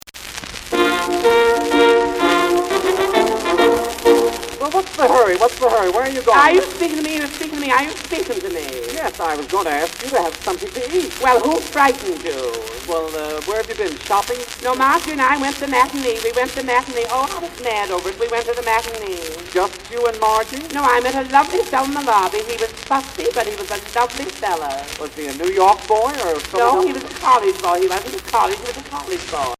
10" 78 rpm